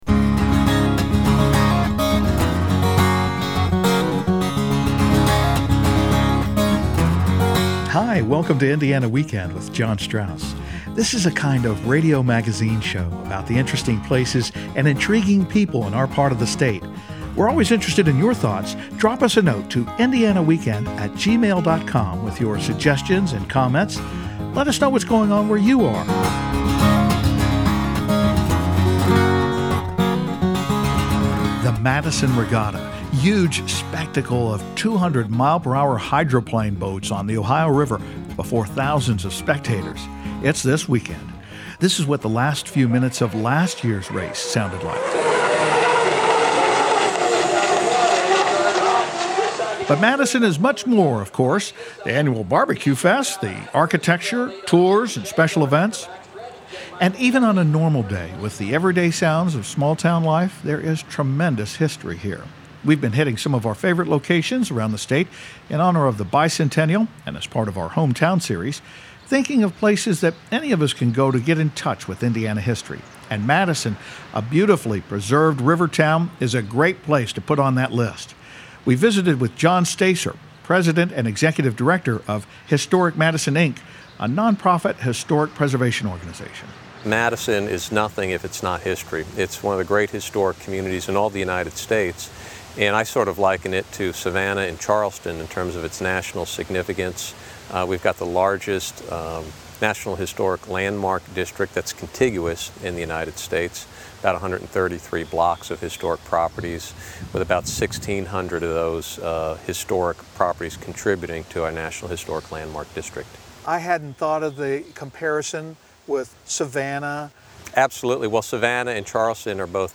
The Madison Regatta, a spectacle of powerboat speed on the Ohio River and subject of a 2005 film, is the destination for thousands of fans this weekend. It’s also set in one of Indiana’s most picturesque and historic towns, and we talk to local folks about what makes the community so special.